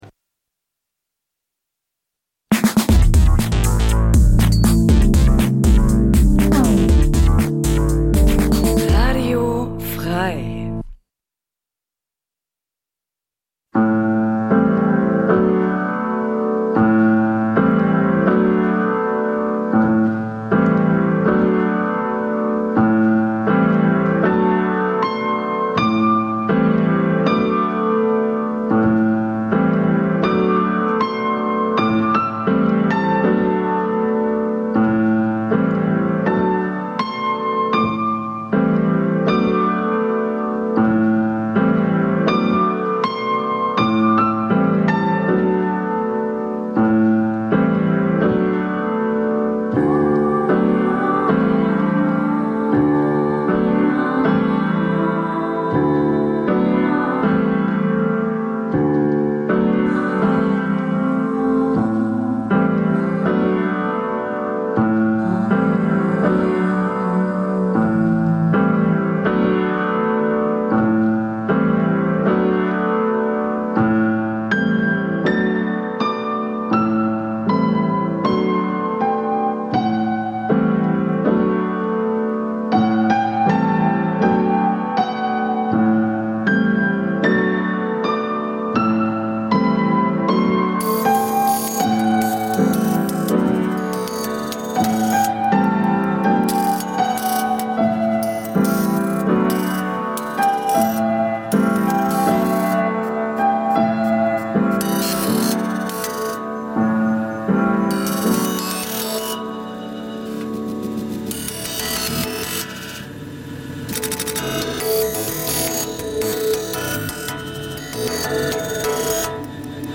Ambient-Musik, oftmals als Fahrstuhlmusik, Hintergrundgedudel oder Kaufhausmusik diskreditiert, er�ffnet uns, bei genauerem Hinblick, eine neue Form des musikalischen Erlebnisses. Die meist ruhigen und getragenen elektronischen Kl�nge, der entschleunigte Charakter dieser Musikrichtung, erfordern ein �bewusstes Sich-Einlassen� auf die Musik, einen quasi meditativen Akt, der als musikalische Alternative zur modernen Leistungsgesellschaft gesehen werden kann.